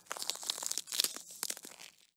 Cloack Active Sound.wav